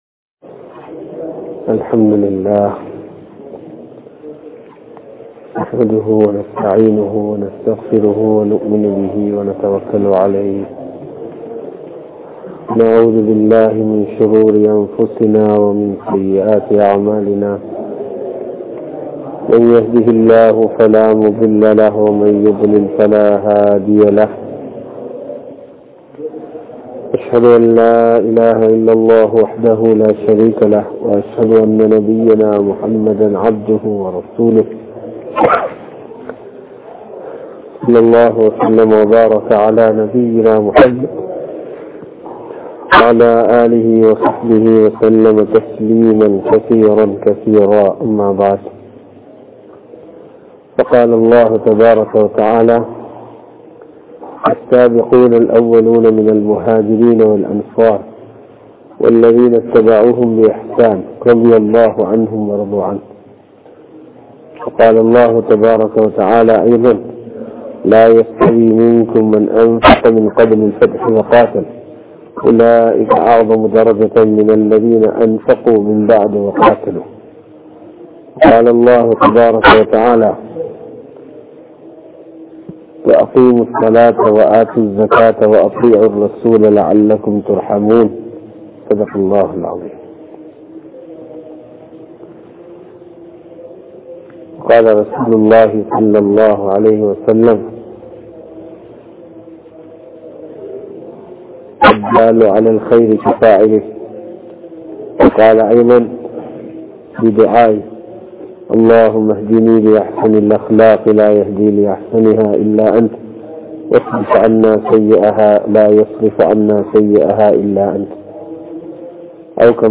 Noor Jumua Masjidh